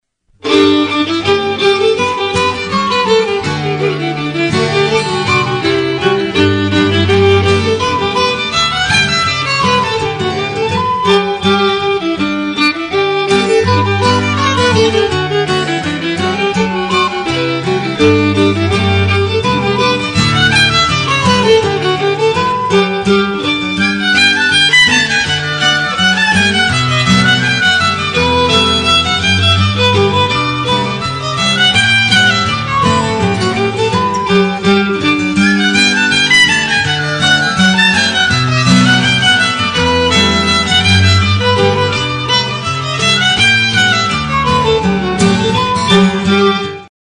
Tinwhistle: Skill Level 1,2
Jigs and Reels
Flowers+of+Edinborough+slow.mp3